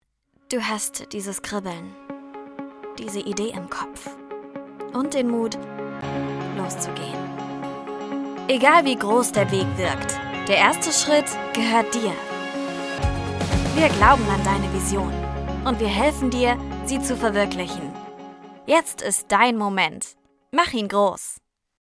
Emotionale Stimme auf Deutsch & Englisch.
• weiblich
• emotional | dynamisch | sanft |
• Junge, frische Klangfarbe, die natürlich und sympathisch klingt